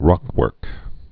(rŏkwûrk)